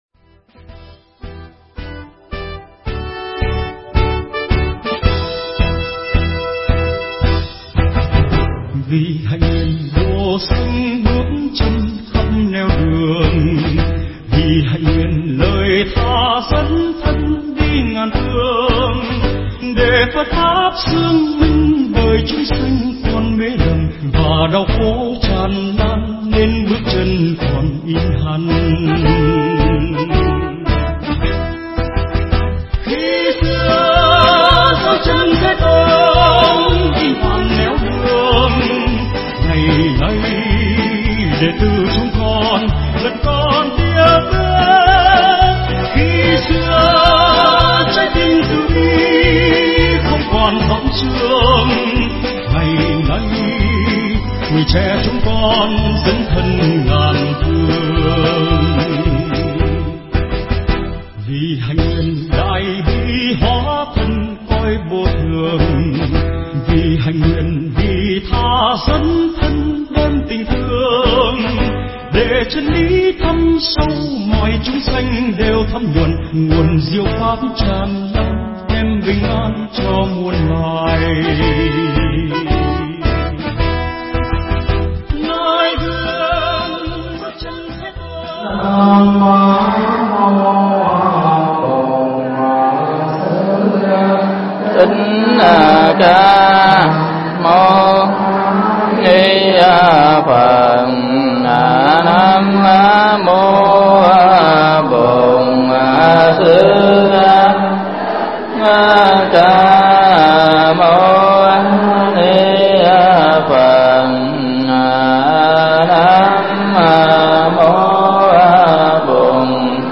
Mp3 Thuyết Giảng Mười pháp sanh Động Tâm (rất hay)
thuyết giảng tại Chùa Long An – Quận Cái Răng , Thành Phố Cần Thơ